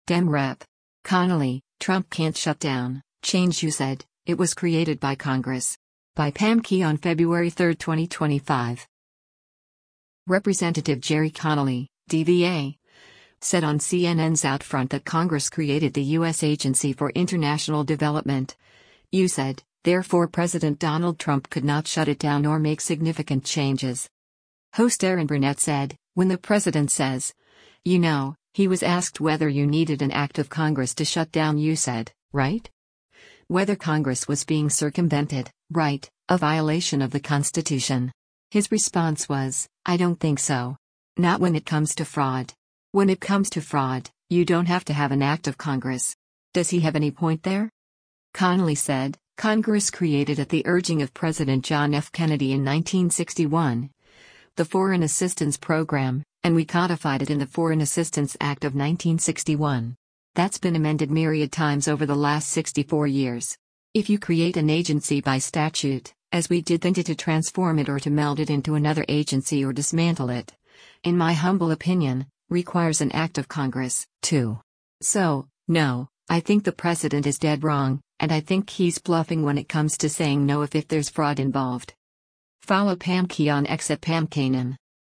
Representative Gerry Connolly (D-VA) said on CNN’s “OutFront” that Congress created the U.S. Agency for International Development (USAID), therefore President Donald Trump could not shut it down or make significant changes.